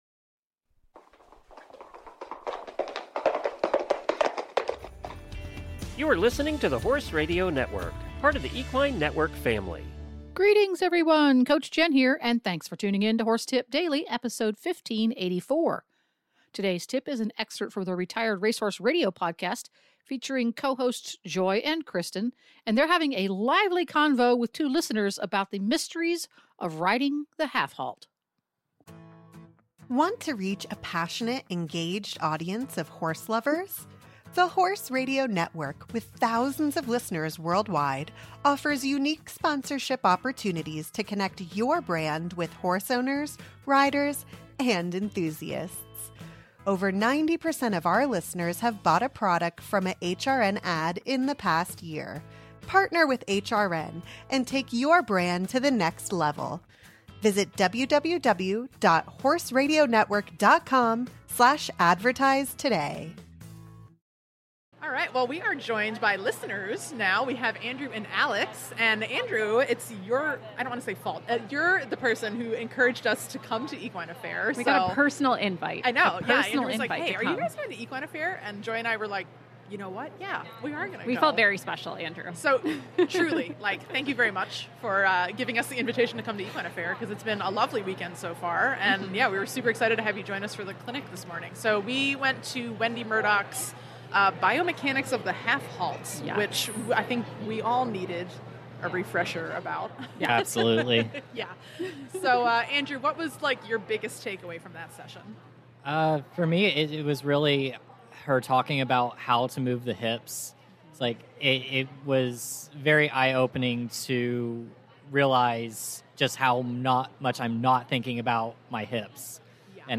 having a lively convo with two listeners about the mysteries of riding the half halt.